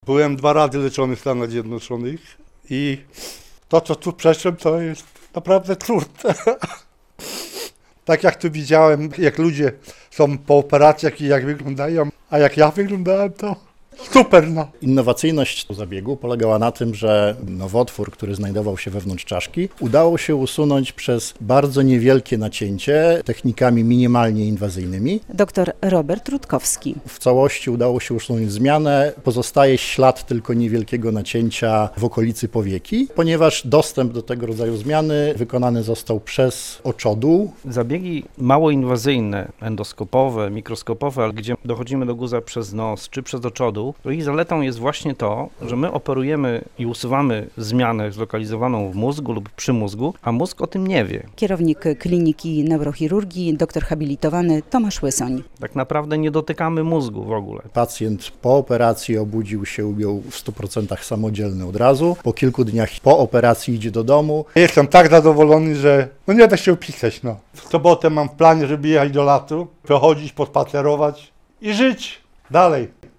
Konferencja prasowa neurochirurgów z Uniwersyteckiego Szpitala Klinicznego w Białymstoku, 7.10.2024, fot.
relacja